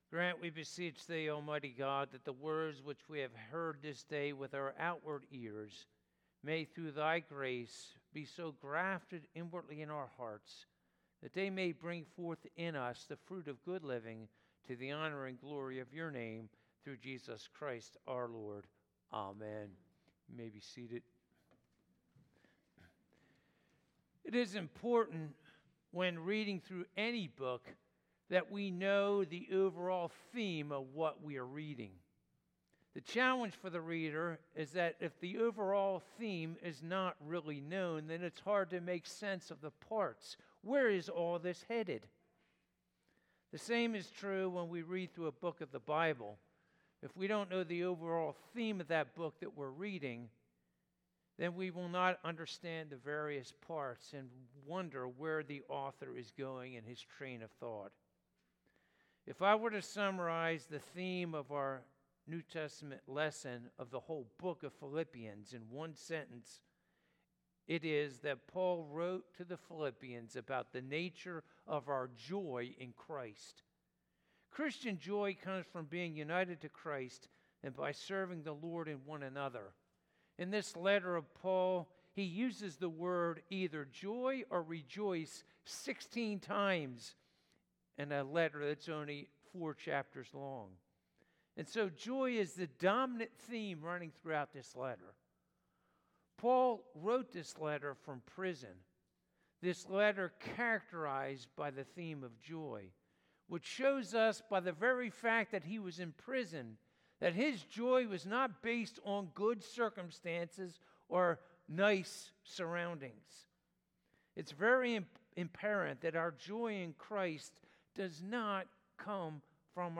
Passage: Philippians 1:3-11 Service Type: Sunday Morning « Putting on the Full Armor of God What is the Purpose of This Miracle?